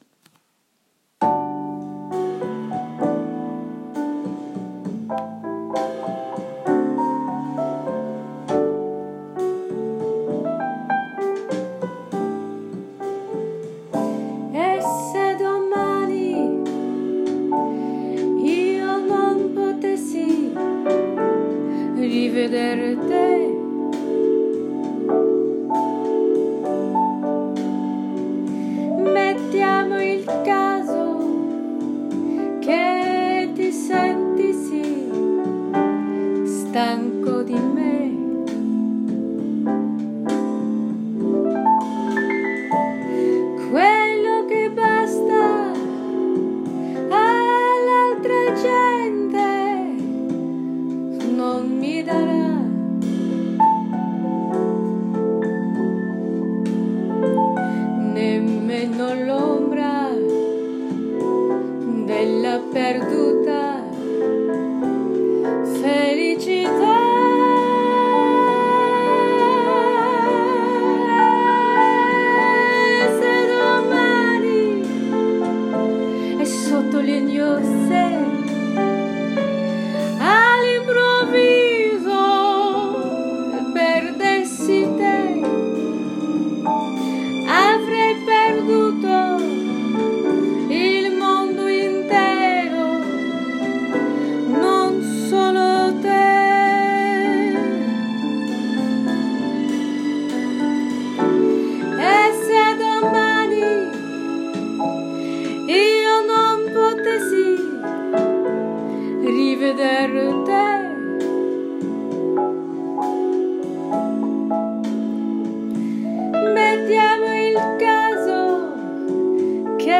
I’m singing an Italian pop song from the 1960s
(Forgive the tacky karaoke orchestration.)